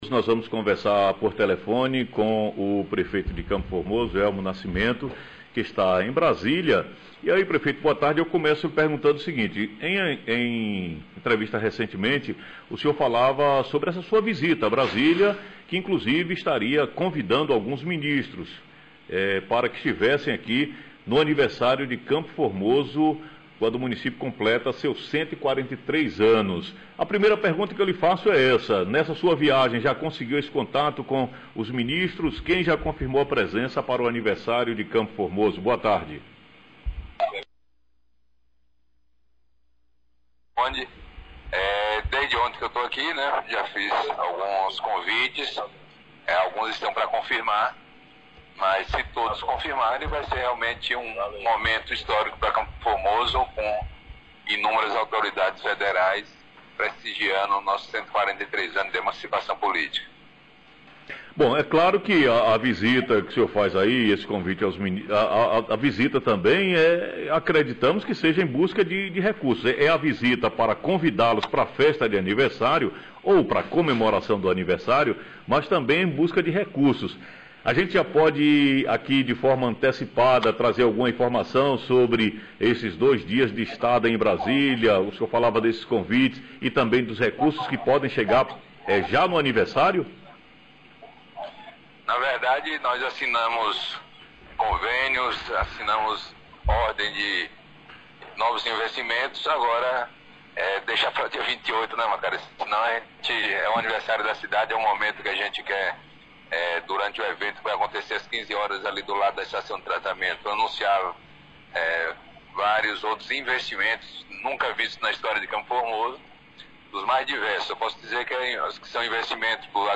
Entrevista com o prefeito Elmo Nascimento pelo telefone fala sobre o objetivo de sua ida à Brasília